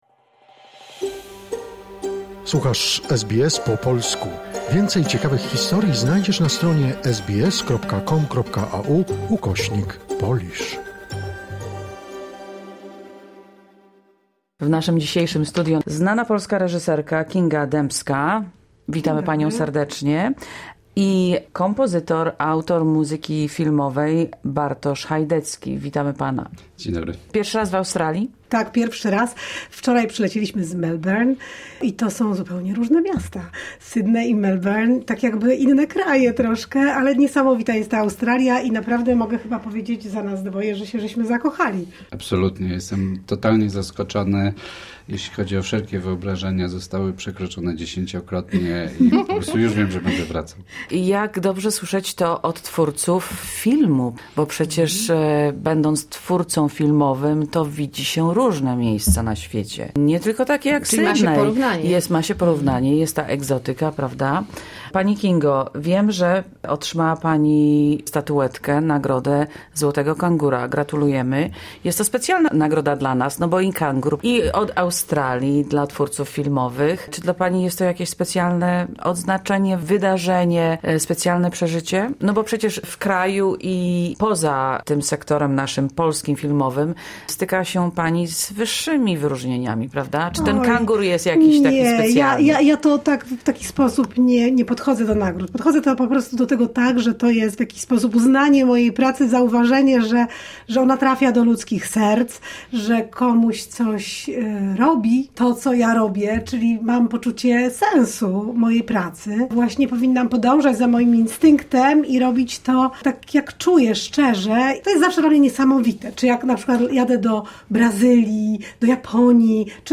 W naszym studio gościliśmy znaną reżyserkę Kingę Dębską i kompozytora Bartosza Chajdeckiego, twórców obrazu „Święto ognia”, którzy przyjechali do Sydney na projeksję filmu.